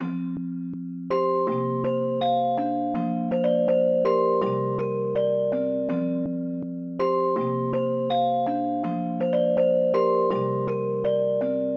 汁液钟声
Tag: 163 bpm Trap Loops Bells Loops 1.98 MB wav Key : F